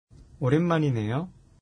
ネイティブの発音を沢山聞いて正しい読み方を覚えましょう。
오랜만이네요 [オレンマニネヨ]